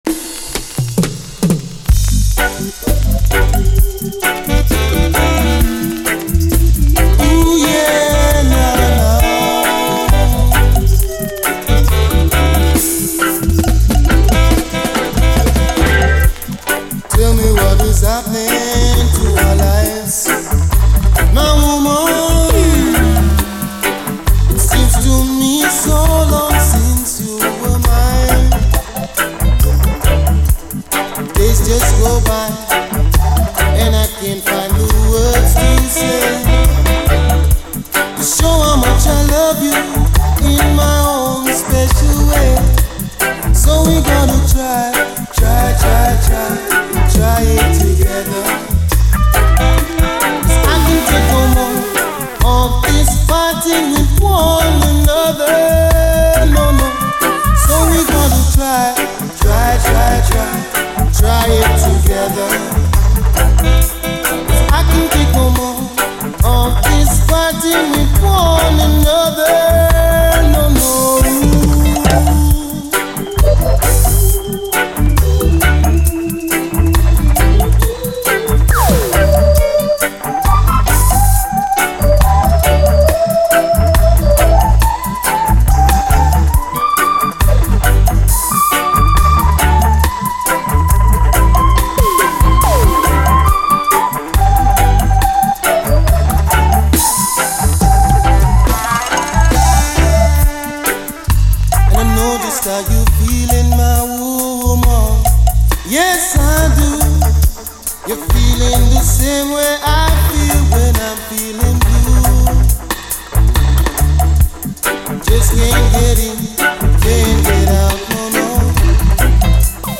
REGGAE
哀愁系UKルーツ・レゲエ！